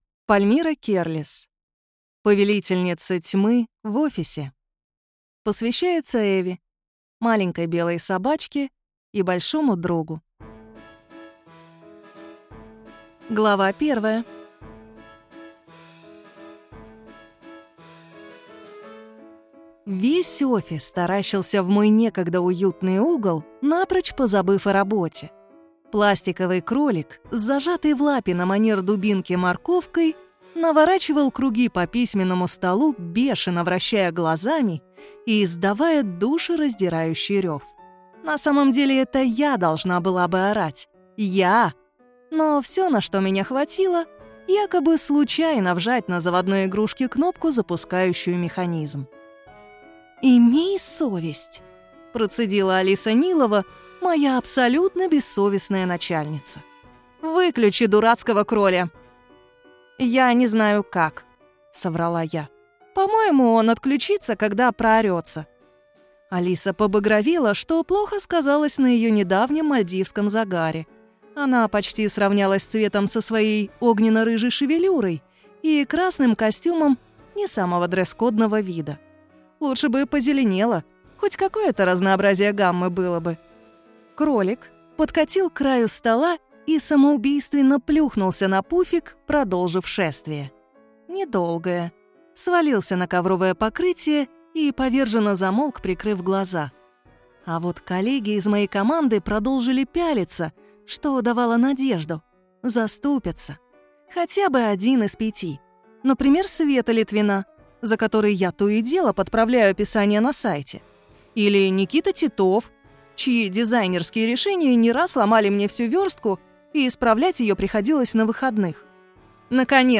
Аудиокнига Повелительница тьмы в офисе | Библиотека аудиокниг